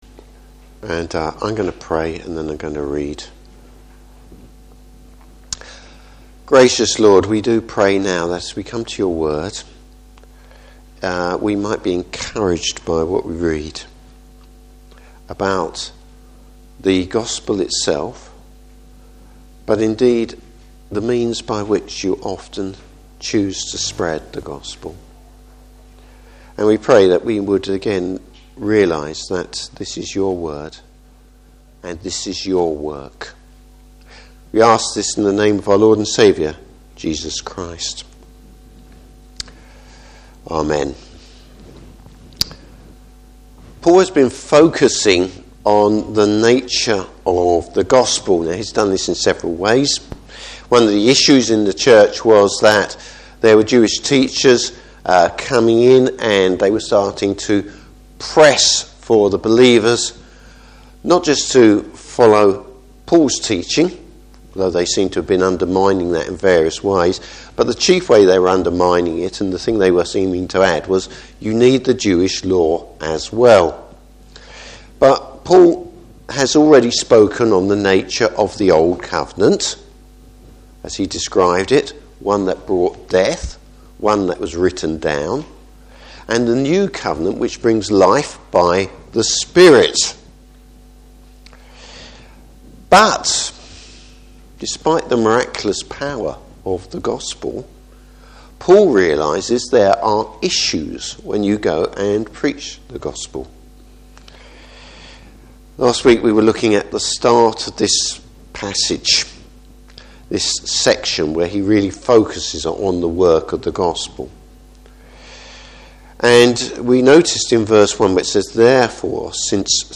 Passage: 2 Corinthians 4:7-12. Service Type: Morning Service An amazing message, ordinary messengers.